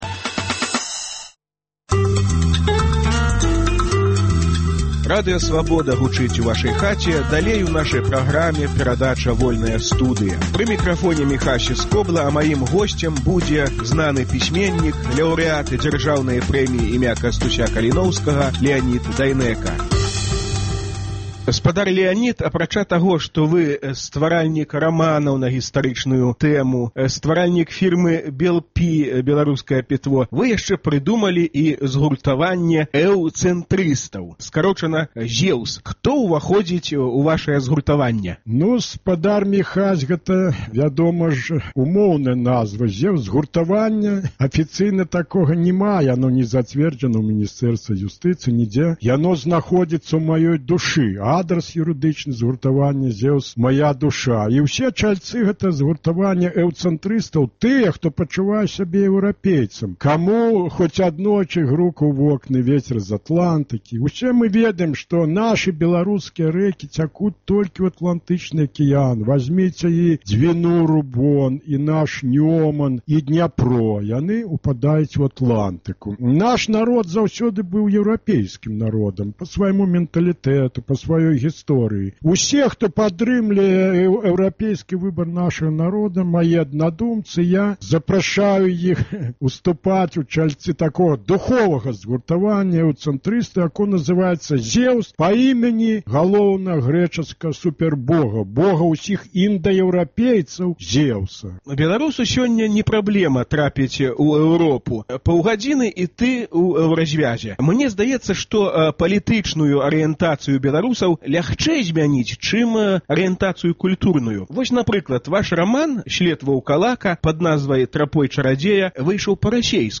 Куды рэкі – туды і людзі: пра атлянтычную скіраванасьць Беларусі гутарка зь пісьменьнікам, ляўрэатам Дзяржаўнай прэміі імя Кастуся Каліноўскага Леанідам Дайнекам.